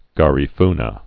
(gärē-fnə)